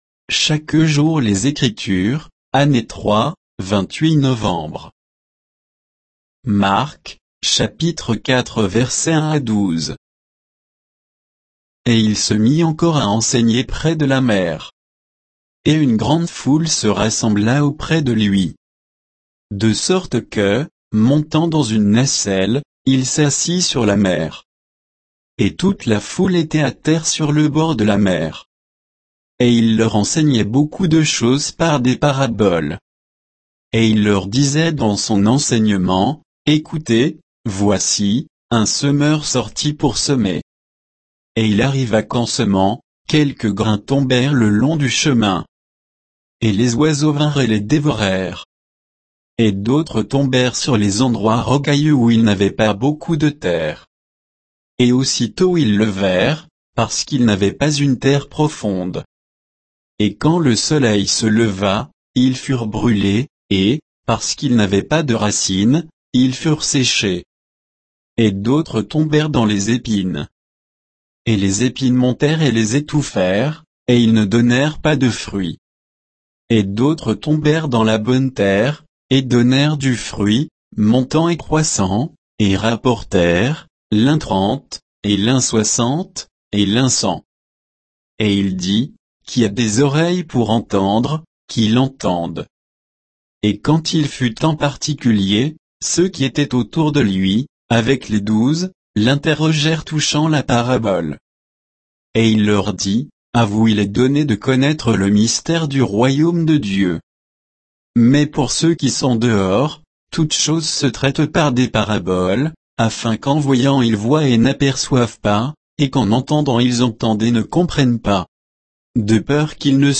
Méditation quoditienne de Chaque jour les Écritures sur Marc 4